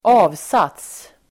Uttal: [²'a:vsat:s]